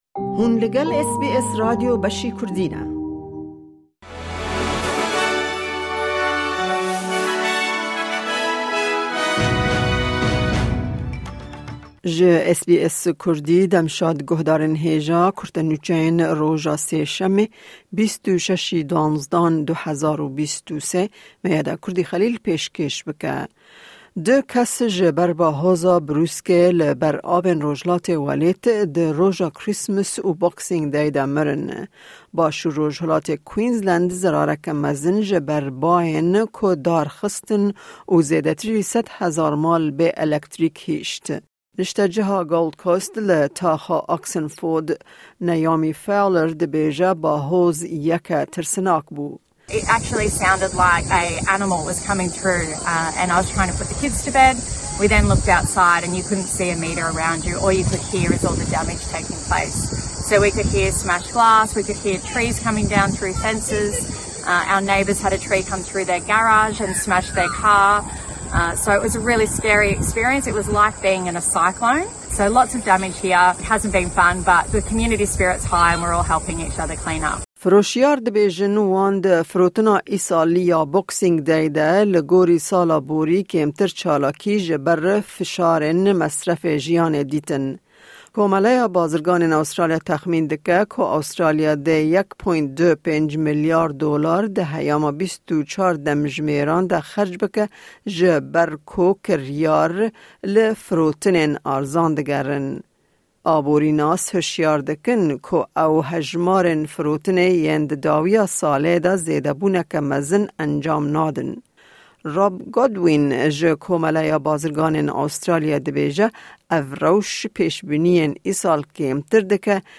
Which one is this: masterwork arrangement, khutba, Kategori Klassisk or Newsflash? Newsflash